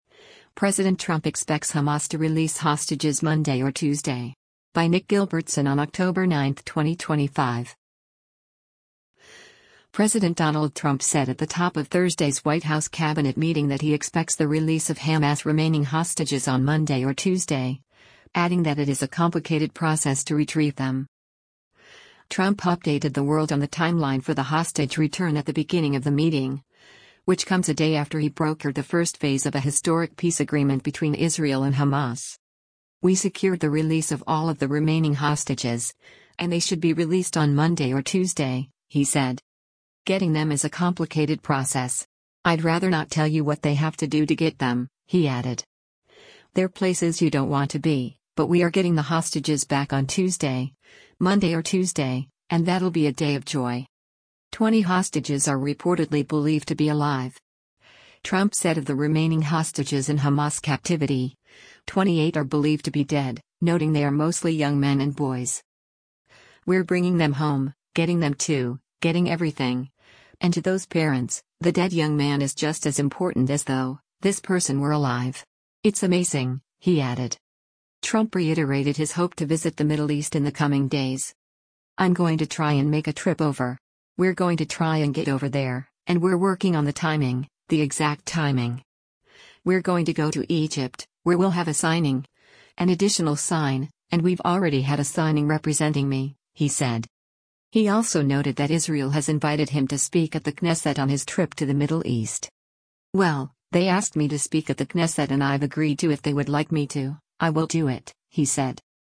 President Donald Trump said at the top of Thursday’s White House cabinet meeting that he expects the release of Hamas’s remaining hostages on Monday or Tuesday, adding that it is a “complicated process” to retrieve them.